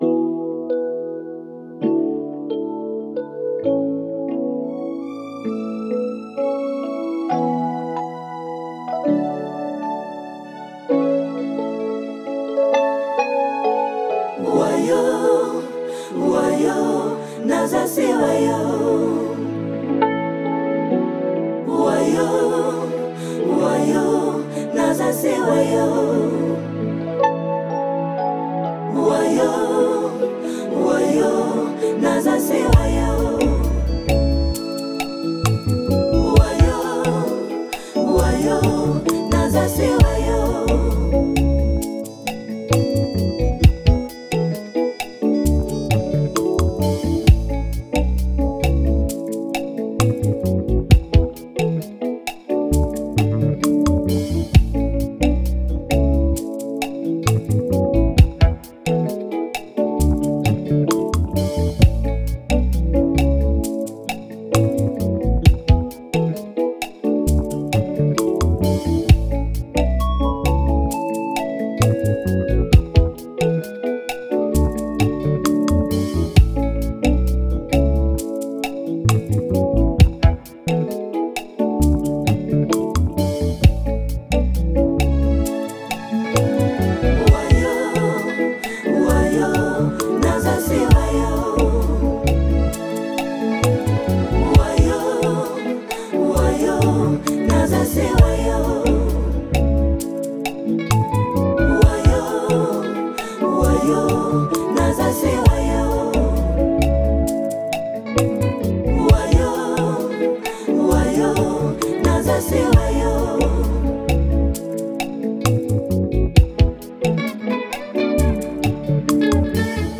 À travers ses albums de slam